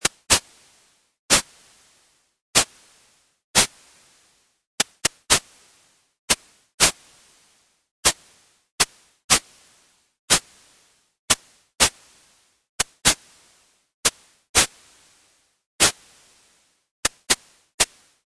Noise bursts for notes
Its timbre is white noise from random sample values
The ChiffInst envelope has a quick attack and decay providing a burst of sound, followed by a low level of noise continuing through
This effect is similar to the noise characteristic of many blown instruments such as pipes and flutes.